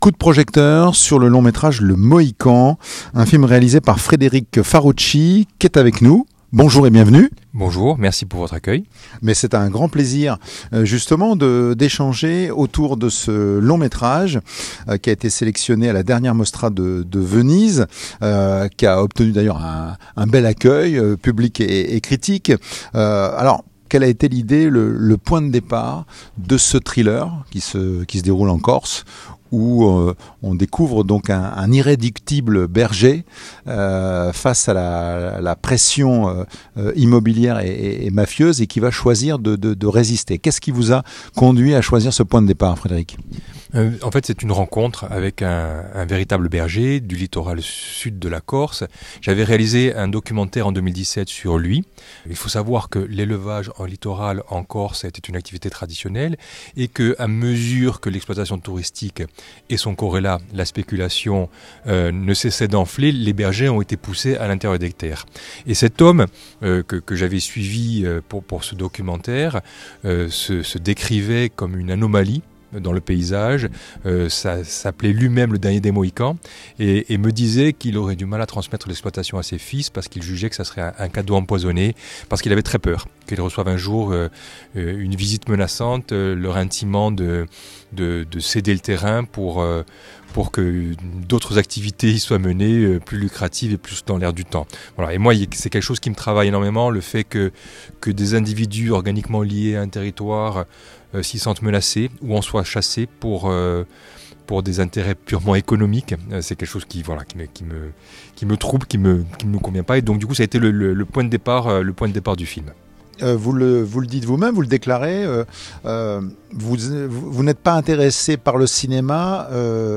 Les podcasts, interviews, critiques, chroniques de la RADIO DU CINEMA